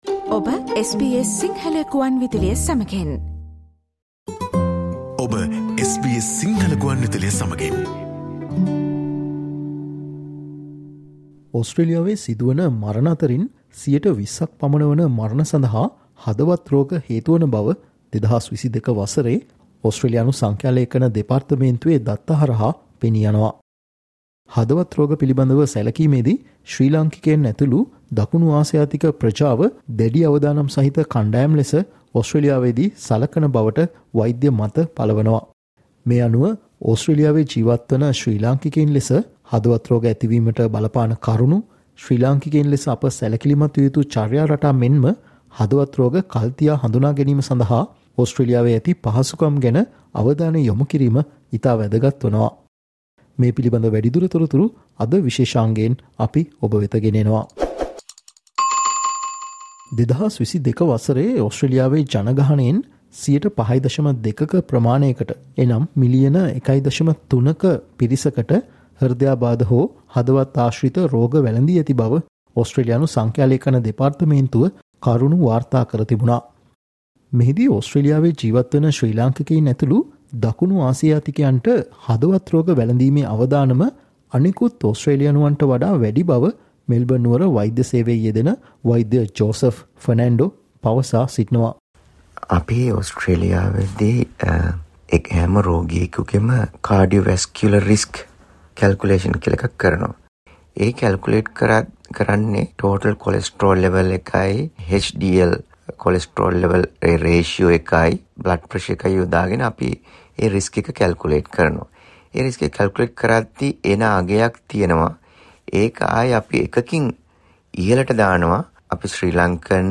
Listen to the SBS Sinhala discussion on maintaining a healthy heart for Sri Lankans living in Australia